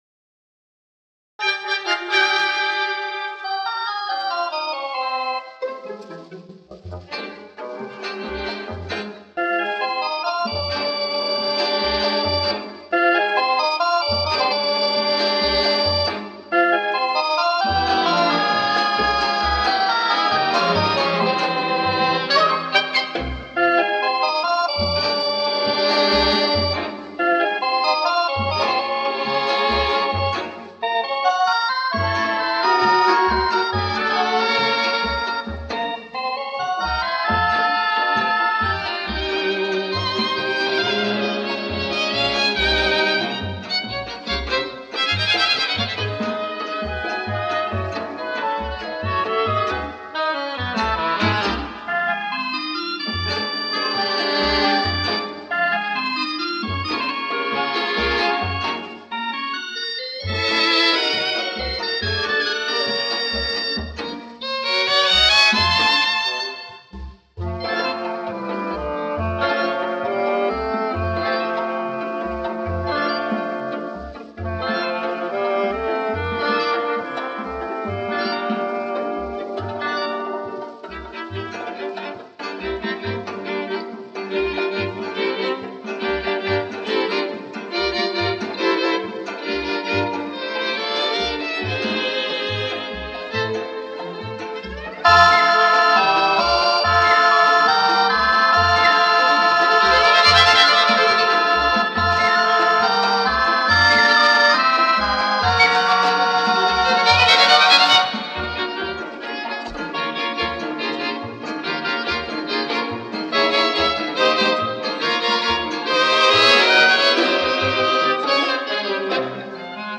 Звук нормализован но пикам, а не по средней громкости.